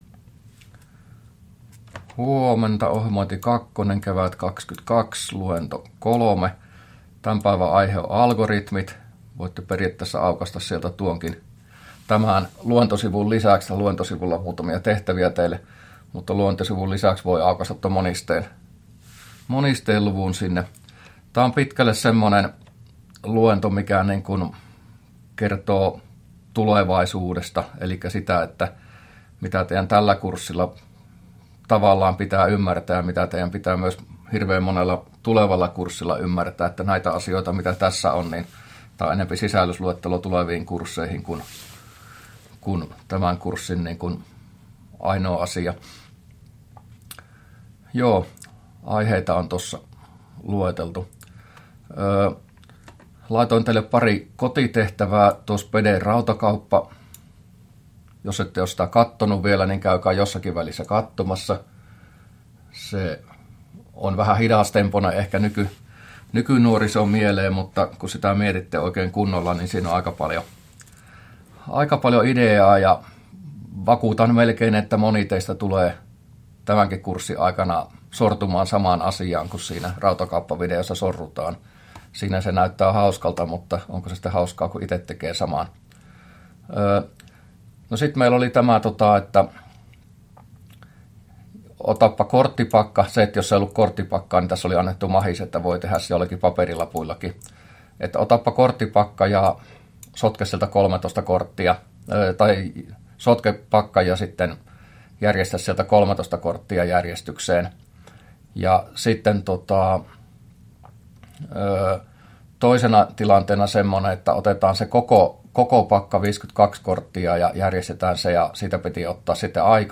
luento03a